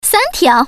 Index of /hunan_master/update/12813/res/sfx/common_woman/